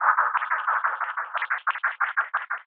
RI_RhythNoise_90-02.wav